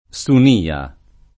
vai all'elenco alfabetico delle voci ingrandisci il carattere 100% rimpicciolisci il carattere stampa invia tramite posta elettronica codividi su Facebook SUNIA [ S un & a ] n. pr. m. — sigla di Sindacato Unitario Nazionale Inquilini e Assegnatari